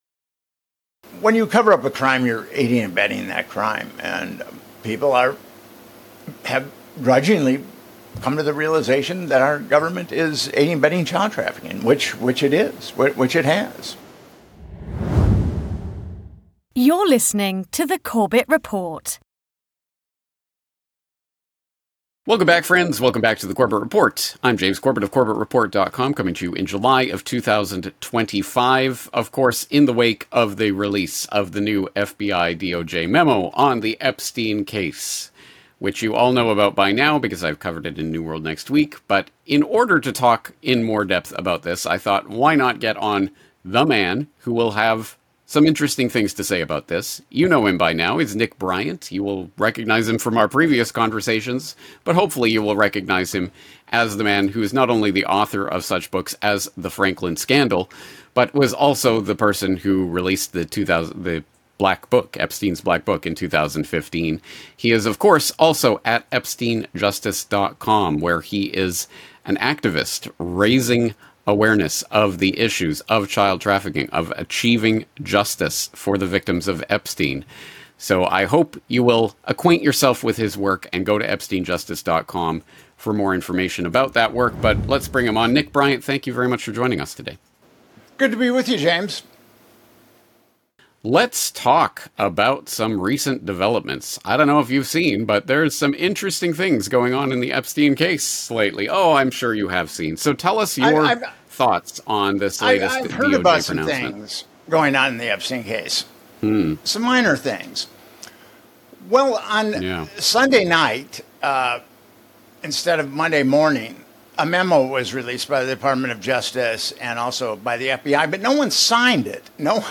Interview 1929